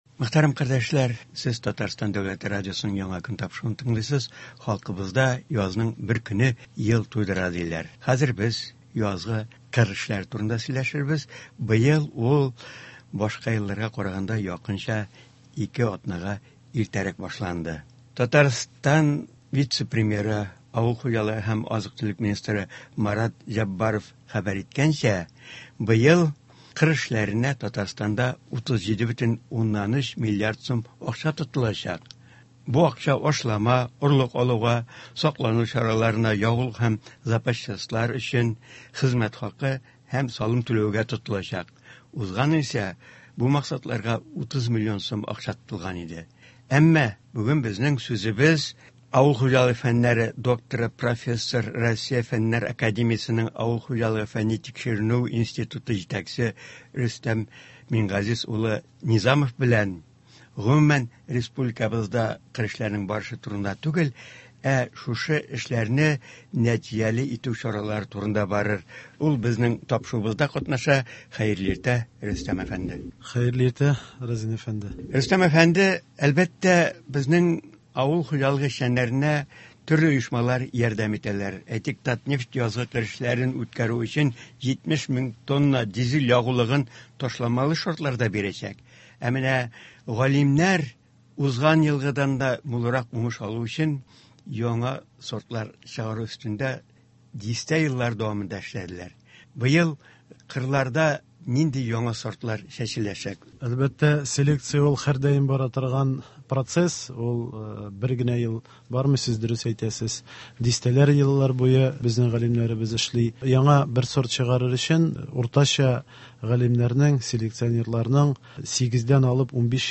Туры эфир (17.04.23)